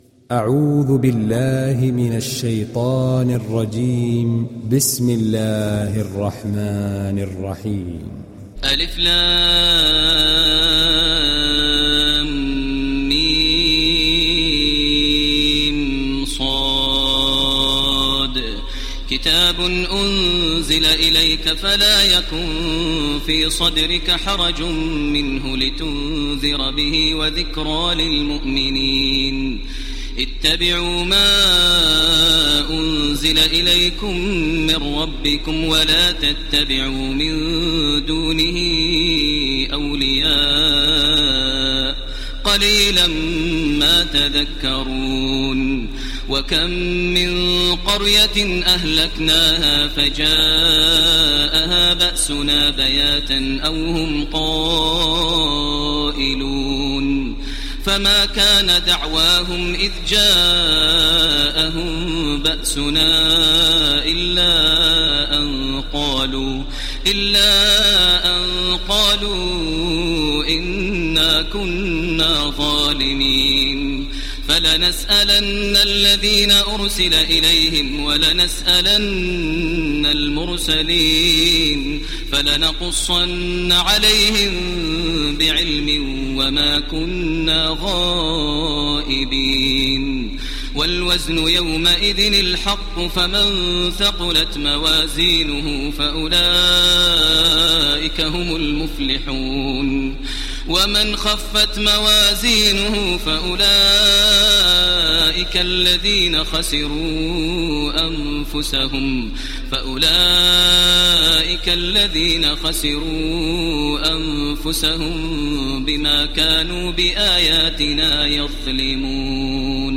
دانلود سوره الأعراف mp3 تراويح الحرم المكي 1430 روایت حفص از عاصم, قرآن را دانلود کنید و گوش کن mp3 ، لینک مستقیم کامل
دانلود سوره الأعراف تراويح الحرم المكي 1430